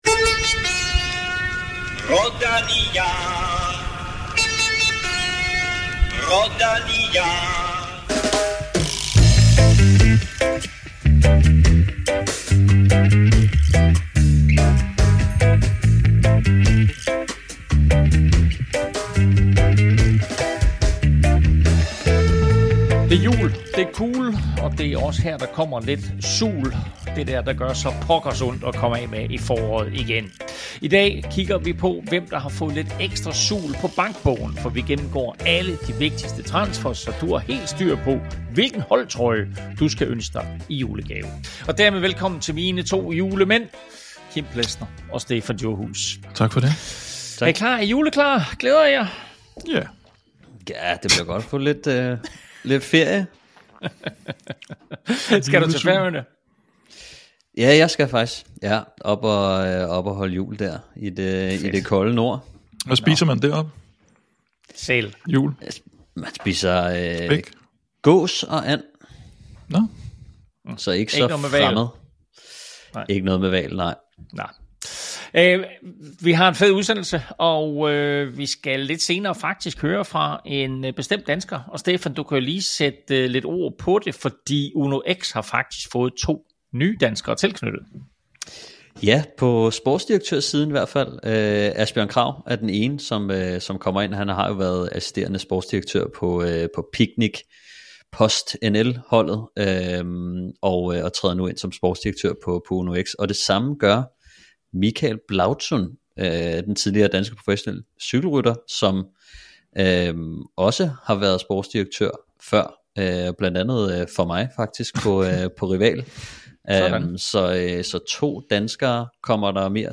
Hvordan har de enkelte mandskaber forstærket sig op til den nye sæson? Og hvilke mandskaber skal finde kreativiteten frem, for at modstå tabet af nøgleryttere? Derudover har vi interview med Michael Blaudzun, der fra den kommende sæson bliver sportsdirektør på det nye World Tour-mandskab, UnoX.